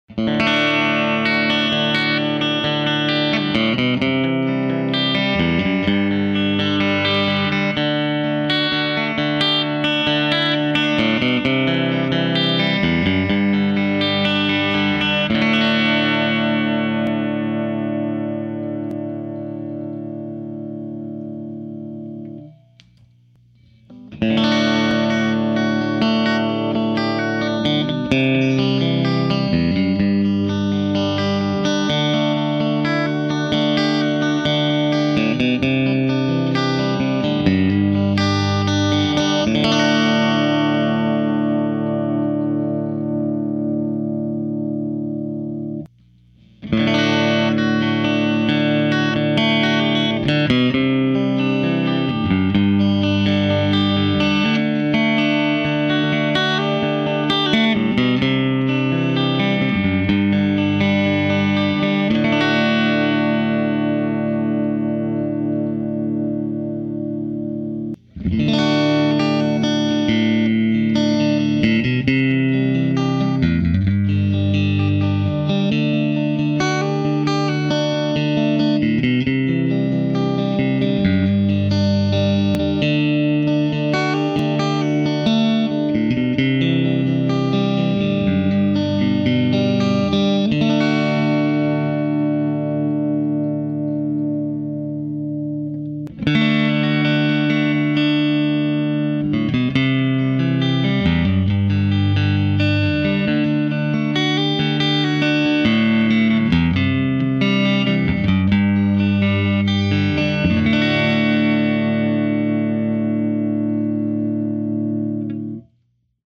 Well, I ended up with just a few minutes to toss up a mic and play a bit. This is just moving through the 5 positions on the Sunburst Strat from bridge to neck and a little shuffle in Amin.
Normal Vol at 4 tone set all at 6 except for Pres at about 2.
Just got a chance to listen to the two clips- great tones and very nice pickin' too!
strat_positions.mp3